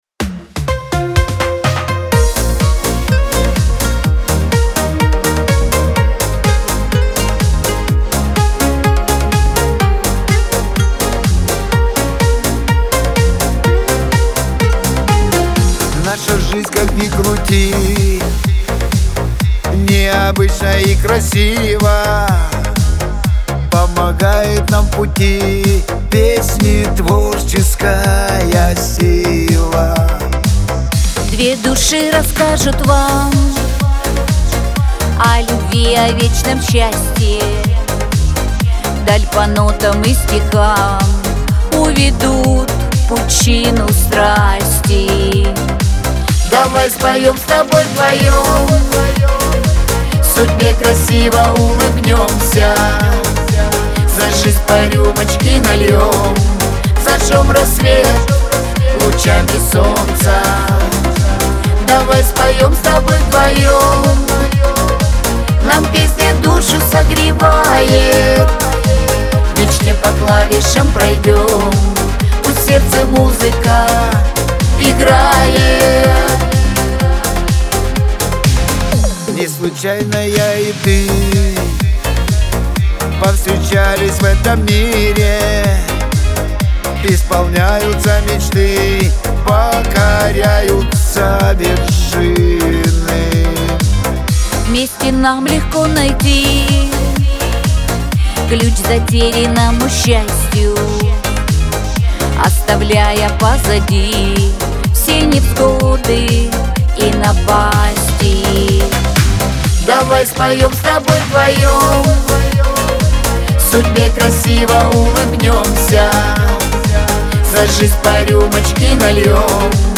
дуэт , pop